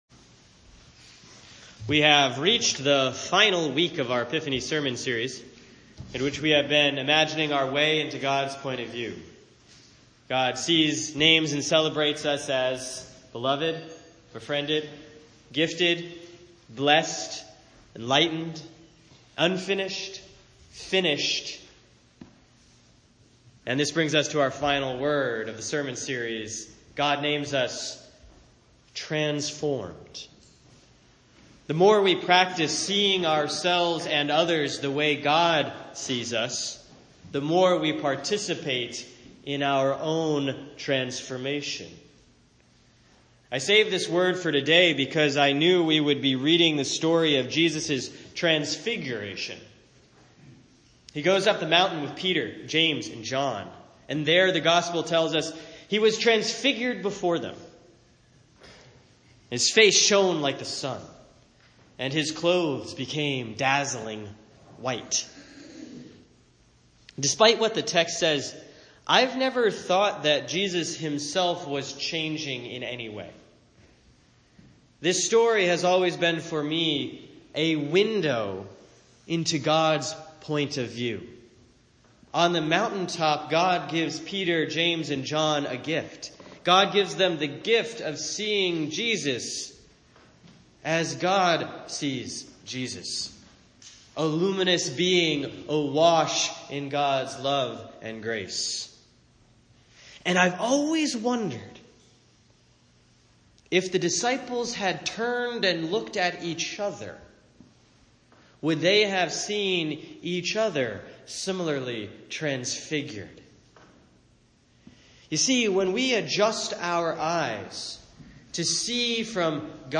The eighth and final week of the Epiphany sermon series on what God sees, names, and celebrates about us. This week: God names us Transformed.